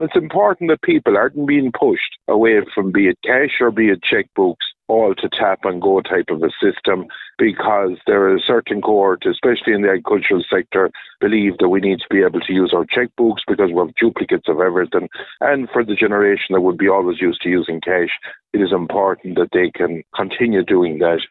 Independent Ireland TD for Roscommon-Galway, Michael Fitzmaurice says access to cash must be protected.